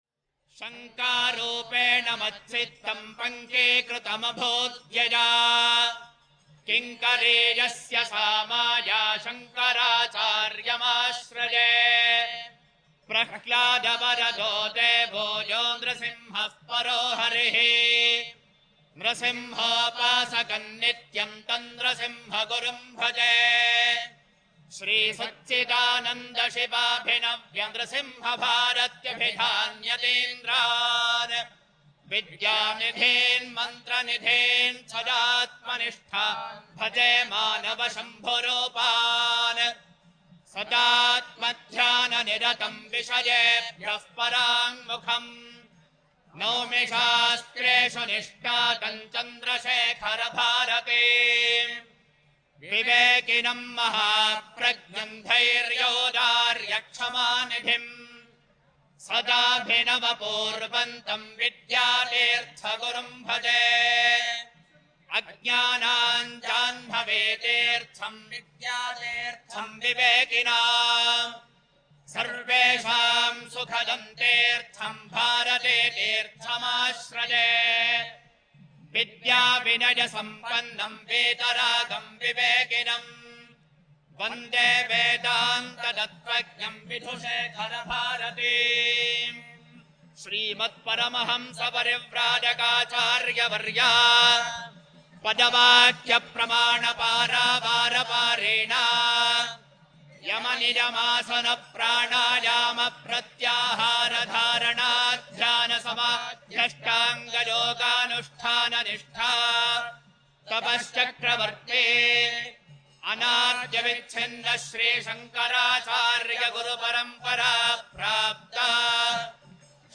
For the benefit of devotees, Jagadguru Sri Shankara Bhagavatpadacharya Puja Kalpa is given below in multiple scripts along with Audio containing the chant of the Puja Kalpa.